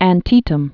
(ăn-tētəm)